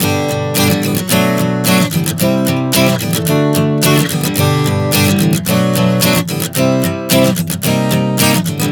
Prog 110 G-Bm-C-D [Cm].wav